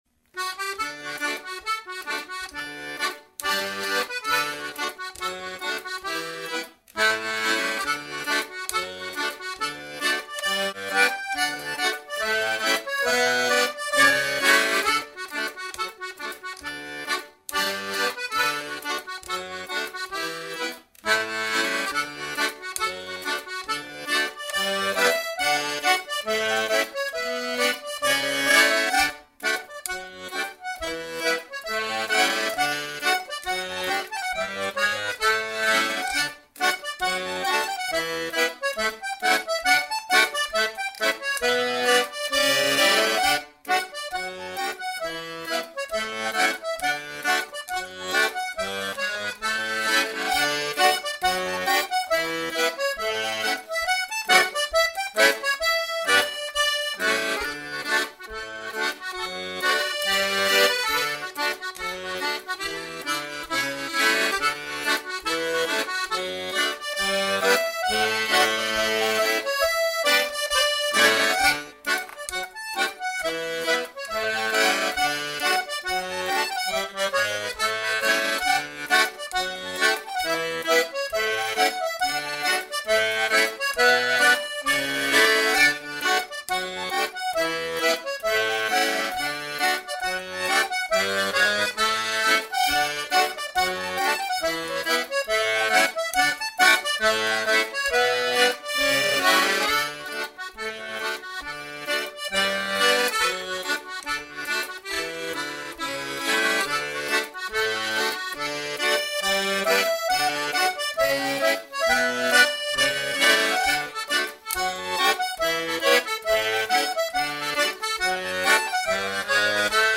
Clog Music 15% slower
Westmorland 160bpm